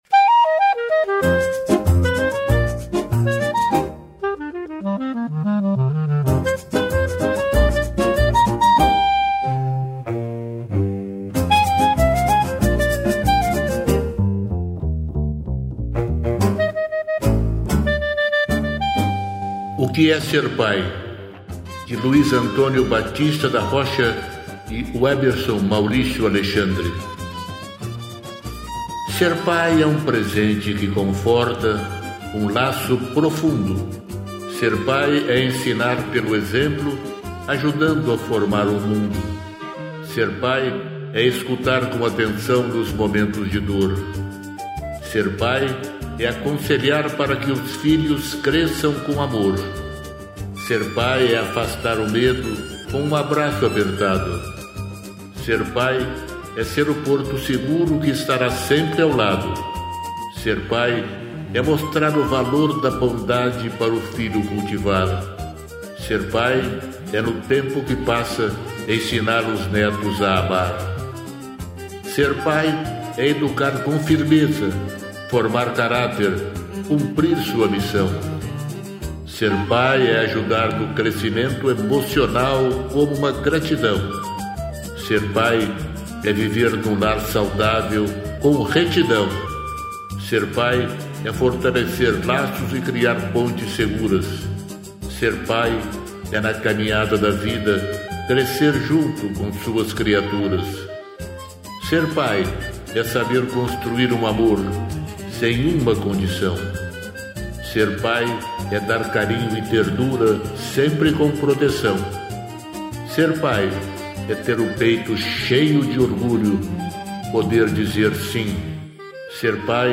música e arranjo: IA